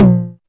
• Mid Tom Drum Single Hit F Key 07.wav
Royality free tom sample tuned to the F note. Loudest frequency: 263Hz
mid-tom-drum-single-hit-f-key-07-ERw.wav